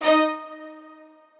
strings3_1.ogg